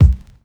• Kickdrum B Key 571.wav
Royality free kickdrum sample tuned to the B note. Loudest frequency: 121Hz
kickdrum-b-key-571-cep.wav